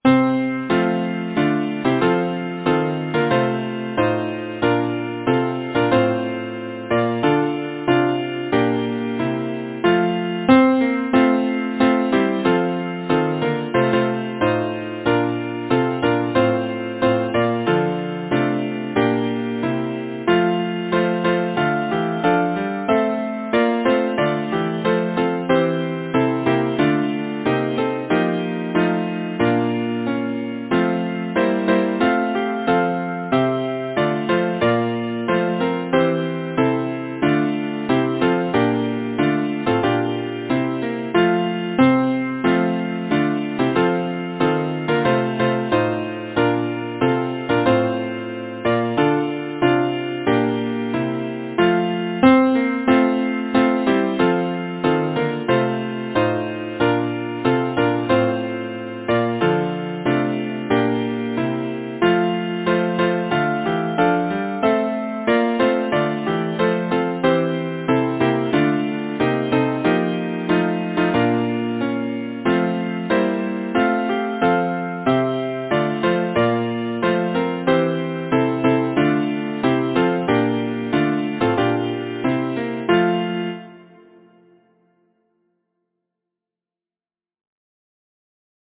Title: Let Erin remember the days of old Composer: Anonymous (Traditional) Arranger: William Rhys-Herbert Lyricist: Thomas Moore Number of voices: 4vv Voicing: SATB Genre: Secular, Partsong, Folksong
Language: English Instruments: A cappella